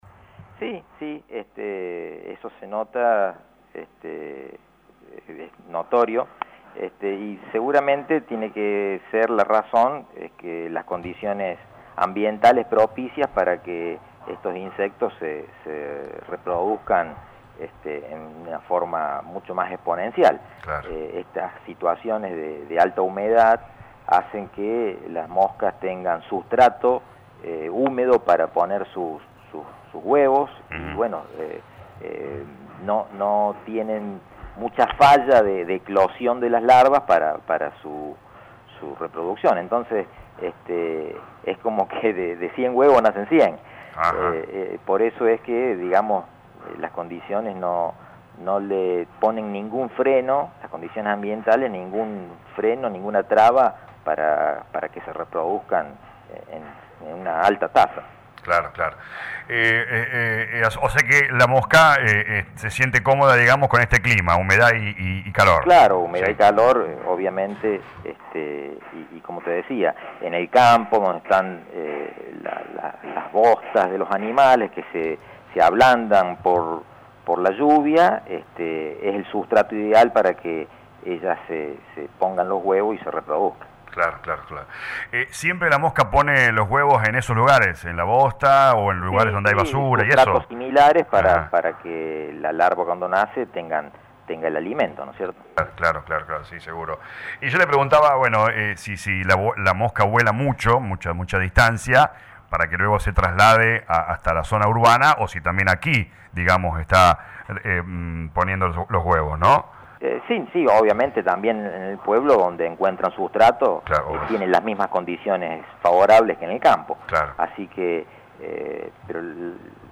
habló con LA RADIO 102.9